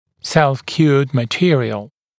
[ˌself’kjuəd mə’tɪərɪəl][ˌсэлф’кйуэд мэ’тиэриэл]самоотверждаемый материал